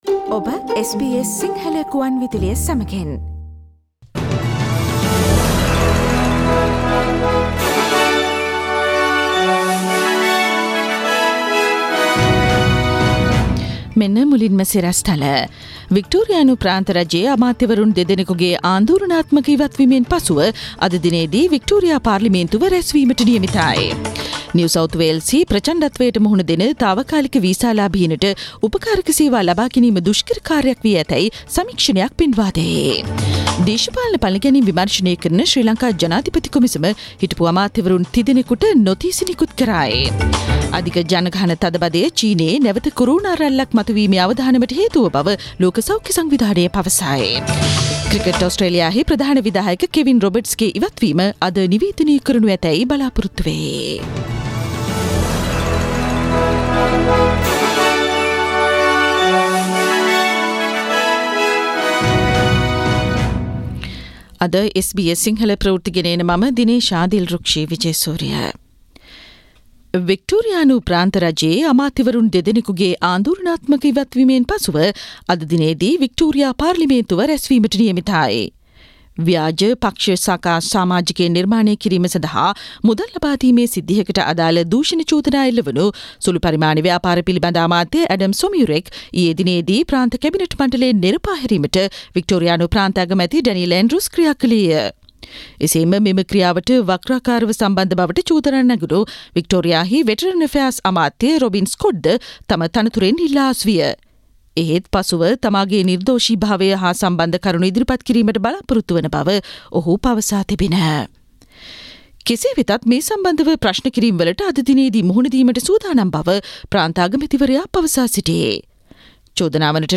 Daily News bulletin of SBS Sinhala Service Source: SBS